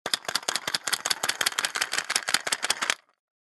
На этой странице вы найдете подборку звуков, связанных с работой фонариков: щелчки кнопок, гудение светодиодов, шум переключателей.
Трясем фонарик чтобы он зажегся